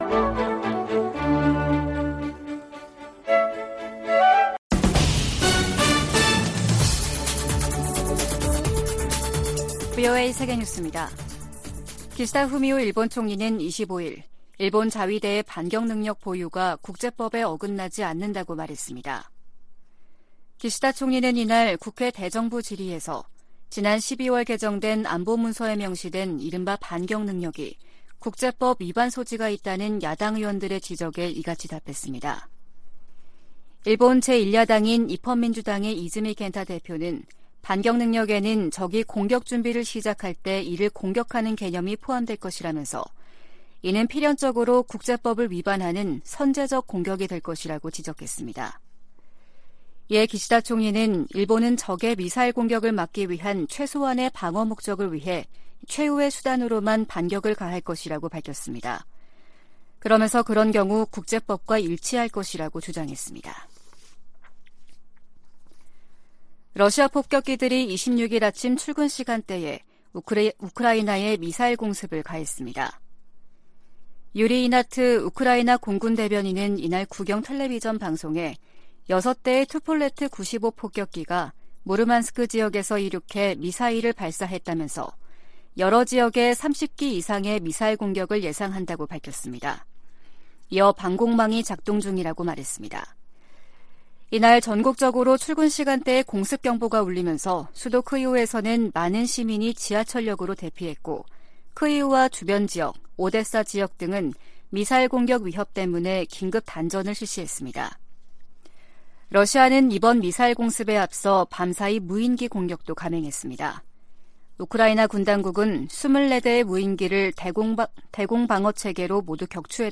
VOA 한국어 아침 뉴스 프로그램 '워싱턴 뉴스 광장' 2023년 1월 27일 방송입니다. 유엔군사령부는 한국 영공에 무인기를 침투시킨 북한과 이에 대응해 북한에 무인기를 날려보낸 한국 모두 정전협정을 위반했다고 평가했습니다. 유엔인권기구와 유럽연합이 미국 정부의 북한인권특사 지명을 환영했습니다.